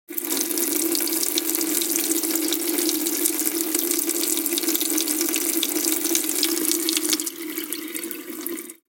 دانلود آهنگ آب 50 از افکت صوتی طبیعت و محیط
دانلود صدای آب 50 از ساعد نیوز با لینک مستقیم و کیفیت بالا
جلوه های صوتی